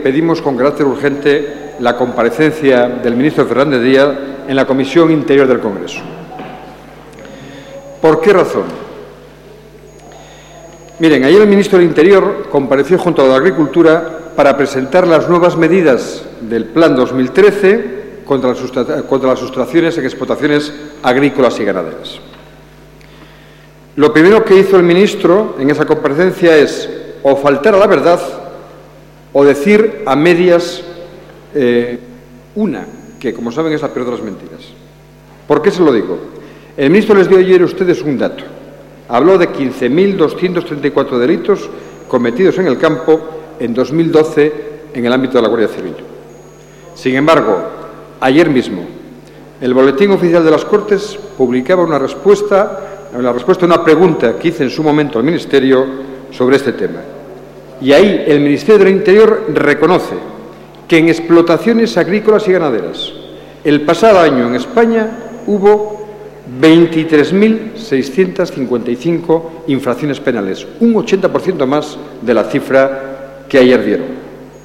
Deeclaraciones de Antonio Trevín sobre los robos en explotaciones agrícolas y ganaderas 26/09/2013